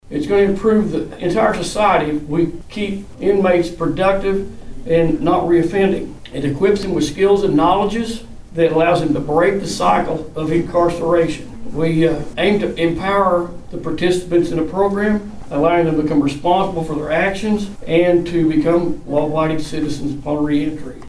The Washington County Sheriff's Office officially announced a partnership with IGNITE on Thursday during a press conference at the new Washington County Emergency Operations Center.
Washington County Sheriff Scott Owen said the initiative will keep inmates productive.